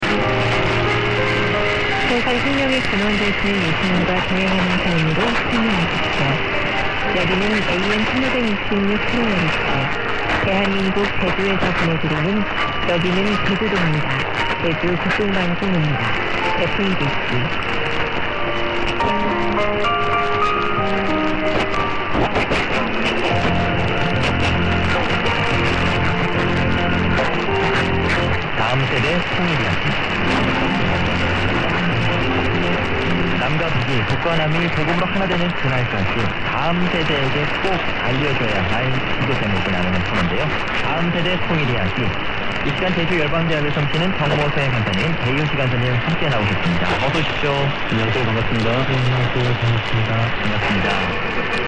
ezt...igen csak távol-keleti hangzása van...
Ez is dél-koreai.